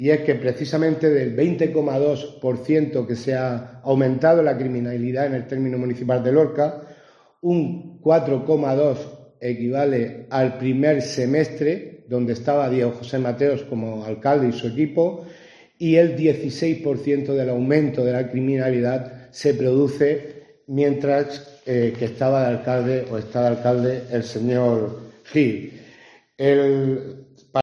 José Luis Ruiz Guillén, concejal del PSOE en Lorca